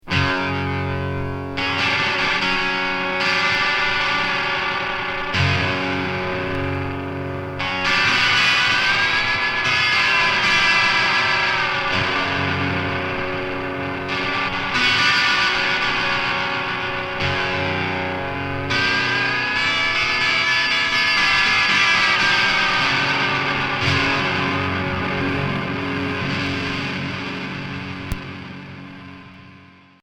Emo core